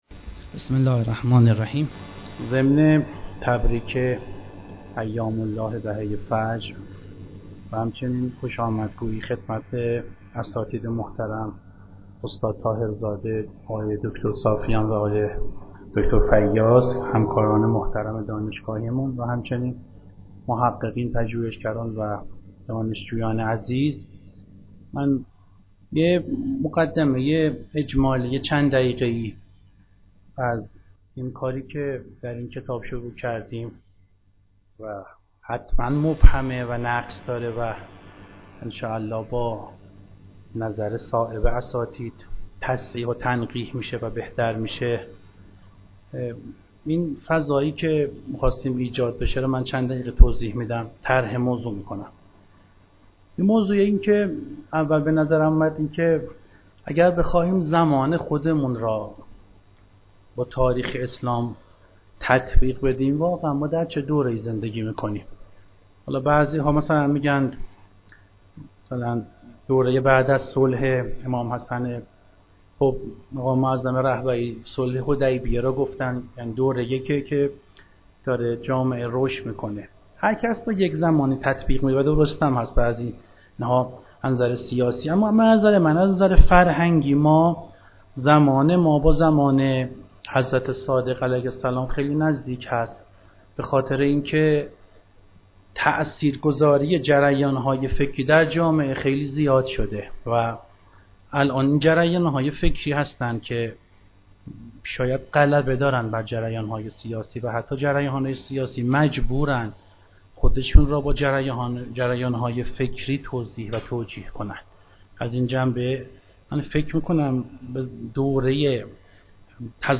مراسم رونمایی کتاب فلسفه تحول تاریخ درخانه بیداری اسلامی - خانه بیداری اسلامی